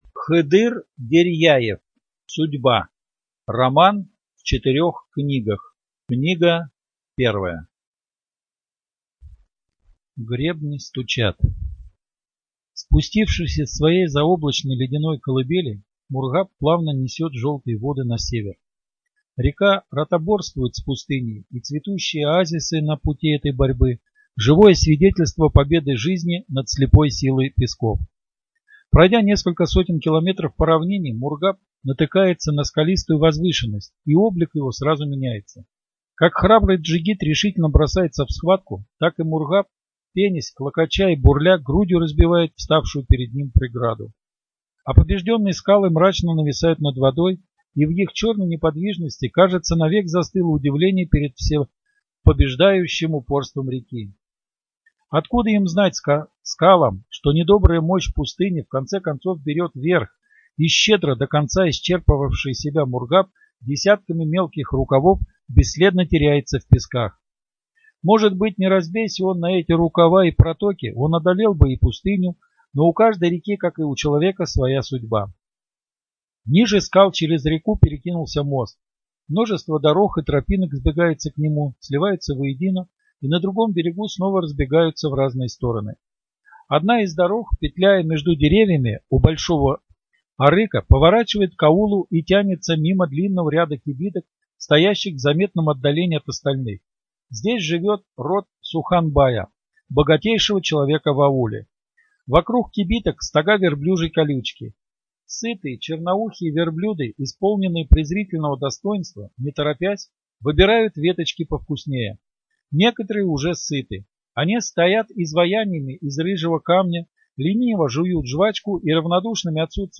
Студия звукозаписиТамбовская областная библиотека имени А.С. Пушкина